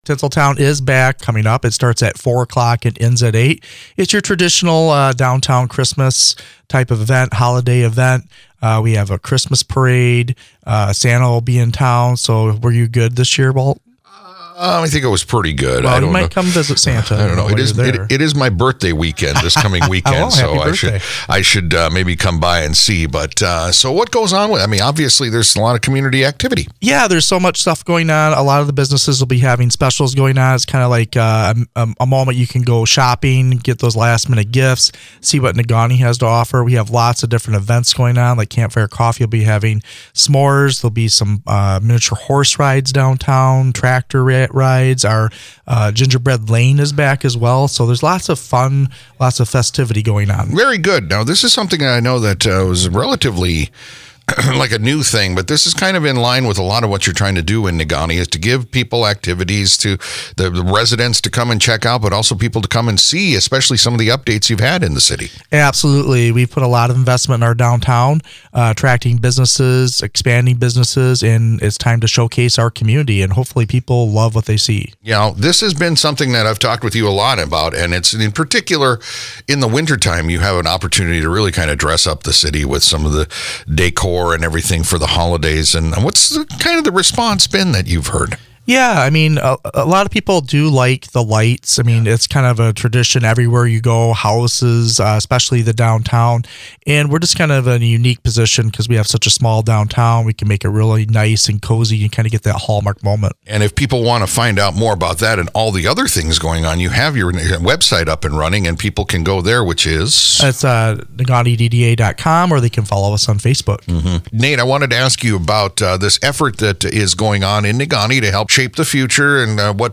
Negaunee City Manager Nate Heffron